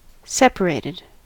separated: Wikimedia Commons US English Pronunciations
En-us-separated.WAV